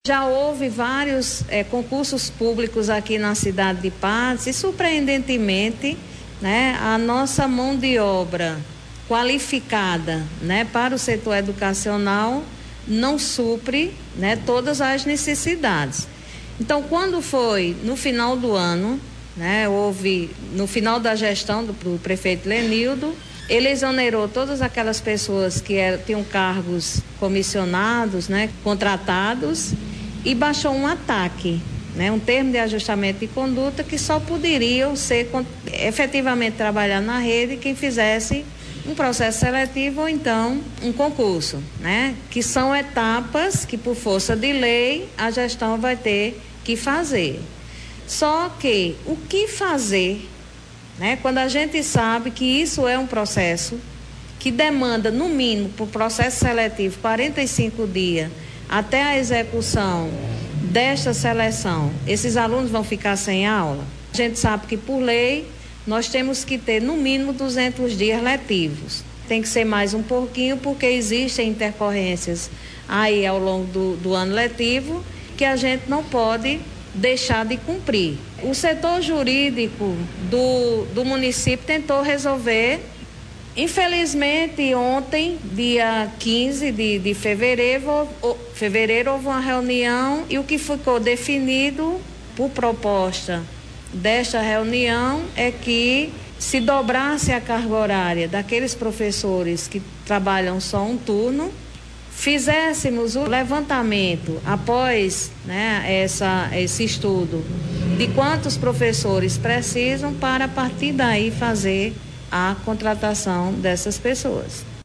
A Secretária de Educação do município de Patos, Alana Candeia, concedeu entrevista nesta quinta-feira, 16, onde esclareceu alguns acontecimentos registrados nos primeiros dias letivos do primeiro ano do governo Dinaldinho Wanderley.